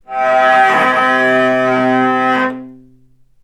healing-soundscapes/Sound Banks/HSS_OP_Pack/Strings/cello/sul-ponticello/vc_sp-C3-ff.AIF at 01ef1558cb71fd5ac0c09b723e26d76a8e1b755c
vc_sp-C3-ff.AIF